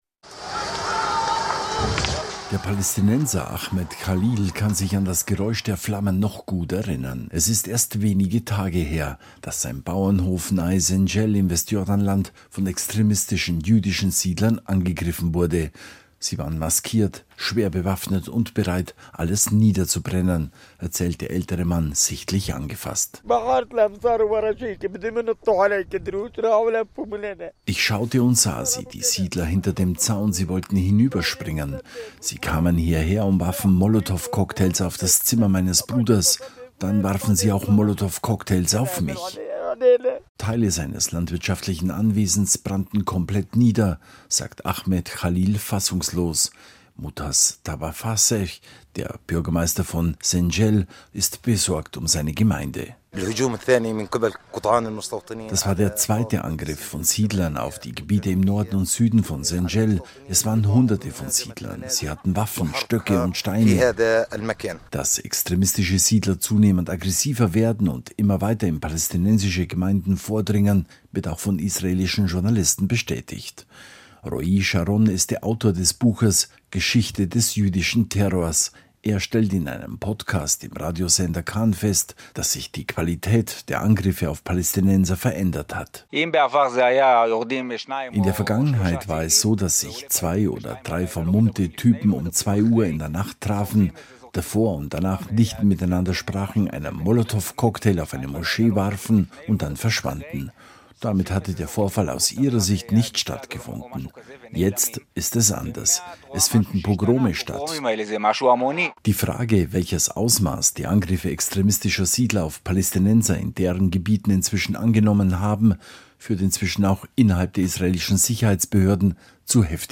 Wir dokumentieren einen Beitrag des Deutschlandfunks, den manche aufgrund der ungünstigen Sendezeit (28.4.2025, 5:20Uhr) vielleicht nicht hören konnten.